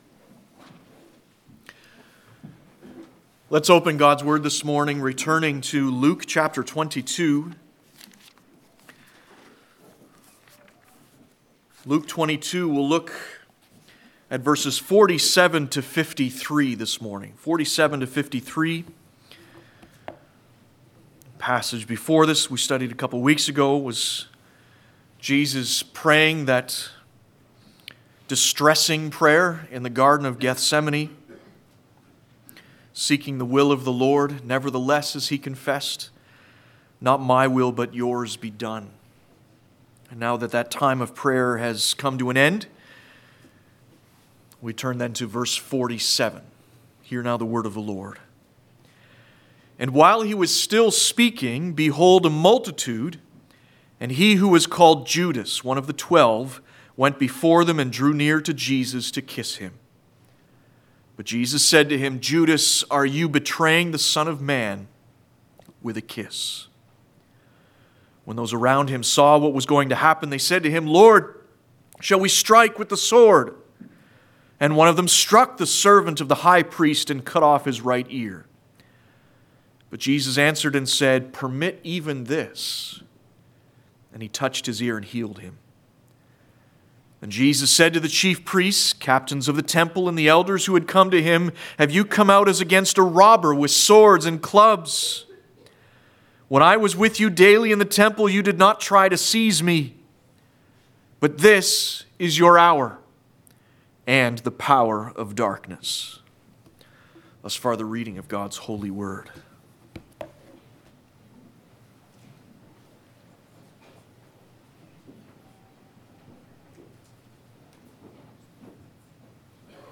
Luke 22-24 Passage: Luke 22:47-53 Service Type: Sunday Morning Topics